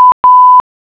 Nota.- Para escuchar el sonido, hacer clic en la clave morse correspondiente (el sonido se escuchará a una velocidad de 10 palabras/minuto).